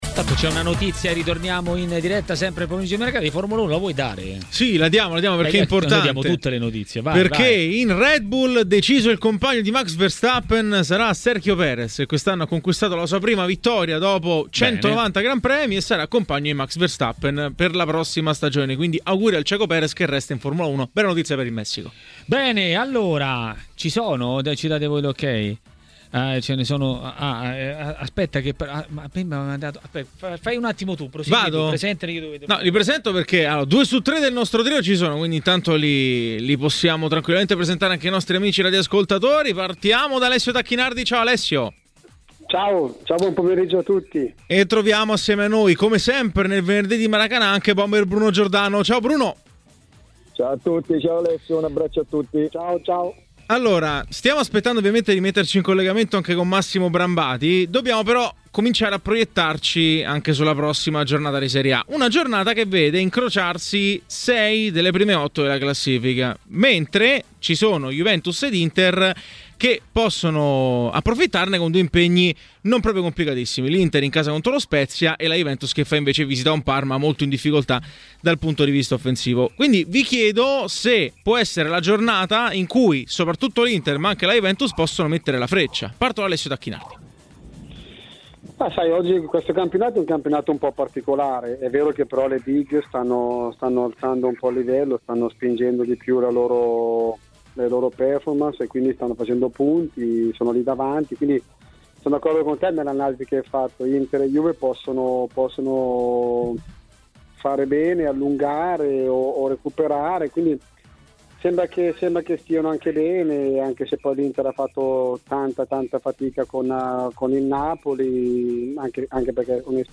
A parlare del prossimo turno di campionato a TMW Radio, durante Maracanà, è stato l'ex bomber e tecnico Bruno Giordano.